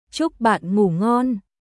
Chúc bạn ngủ ngonチュック・バン・グー・ゴンおやすみ（友達に）